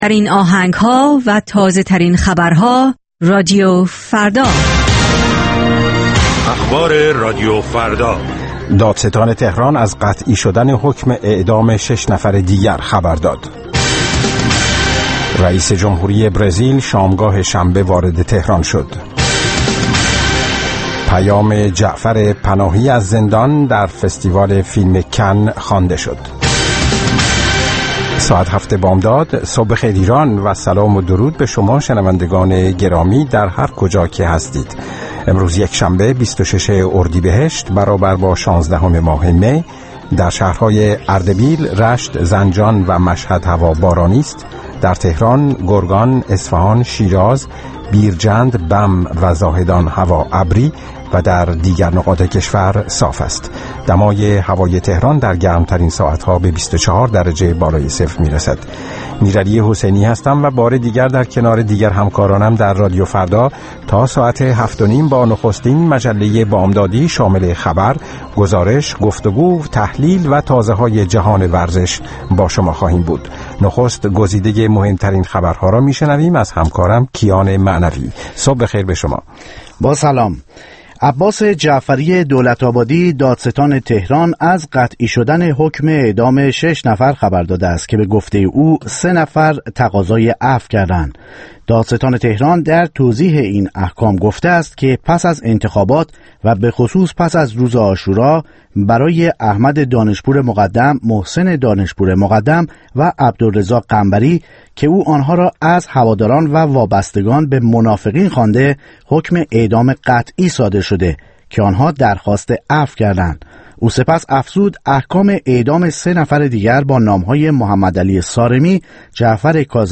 گزارشگران راديو فردا از سراسر جهان، با تازه‌ترين خبرها و گزارش‌ها، مجله‌ای رنگارنگ را برای شما تدارک می‌بينند. با مجله بامدادی راديو فردا، شما در آغاز روز خود، از آخرين رويدادها آگاه می‌شويد.